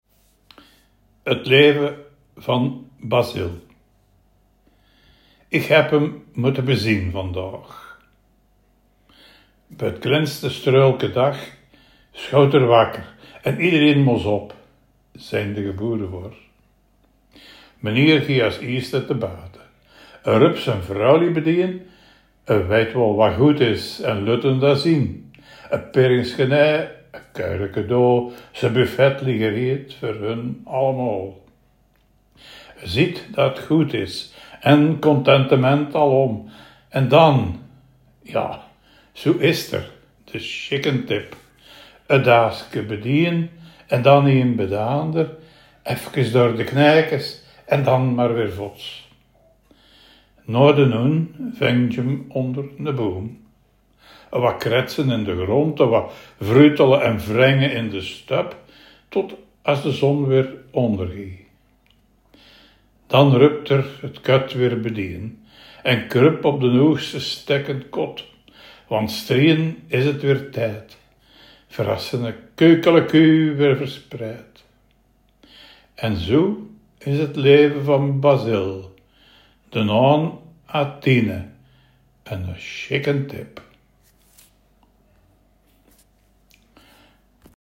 Veldeke Belgisch Limburg | Belgisch-Limburgse dialecten